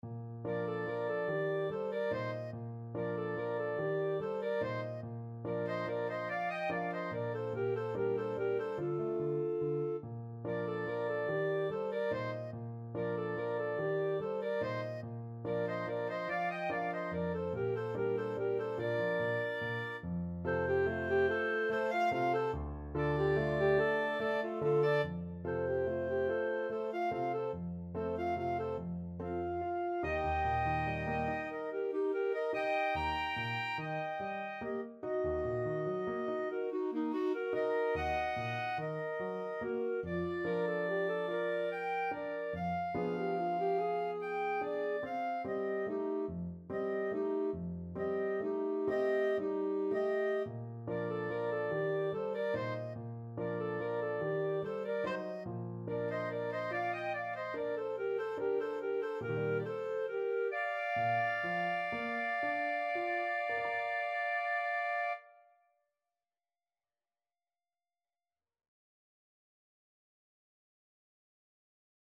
Free Sheet music for Clarinet-Saxophone Duet
ClarinetAlto Saxophone
Bb major (Sounding Pitch) C major (Clarinet in Bb) (View more Bb major Music for Clarinet-Saxophone Duet )
Gently rocking = 144
6/8 (View more 6/8 Music)
Classical (View more Classical Clarinet-Saxophone Duet Music)